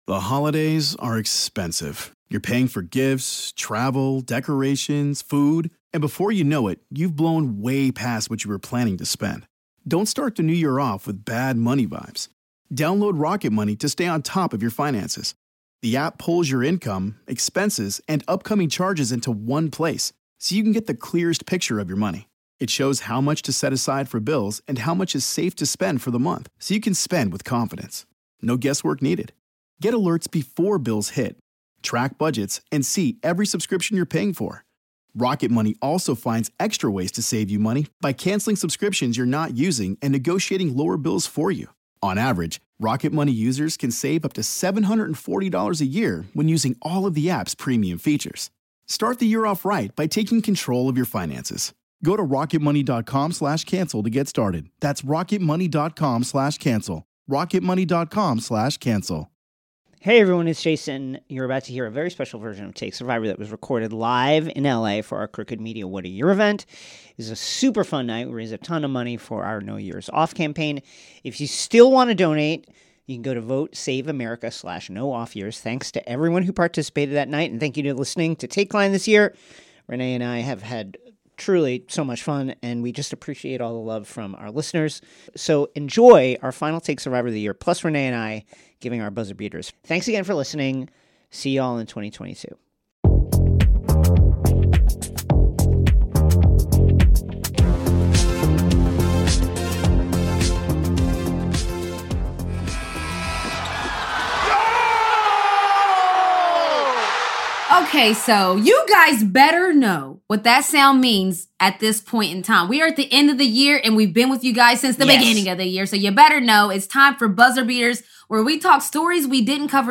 On the last Takeline of 2021 we invite you to listen to a special LIVE version of Take Survivor recorded during our Crooked “What A Year” event taped in Los Angeles.